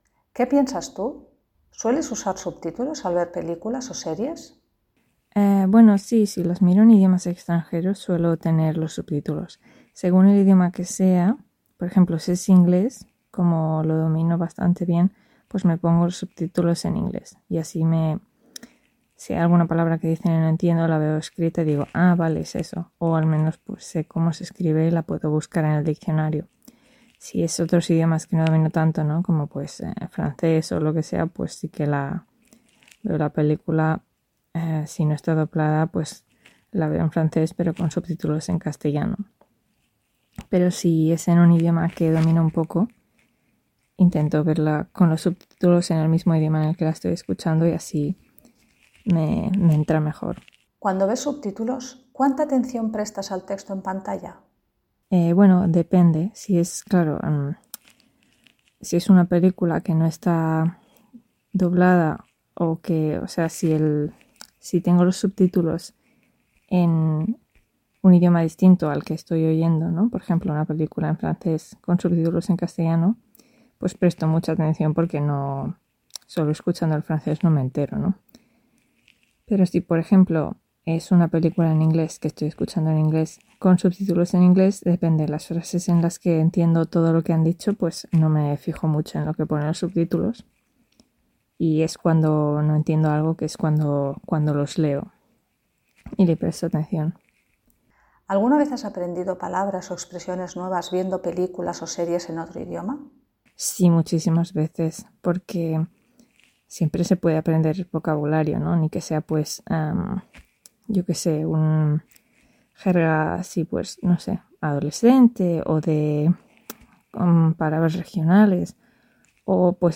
Ahora escucha a un nativo que contesta las preguntas.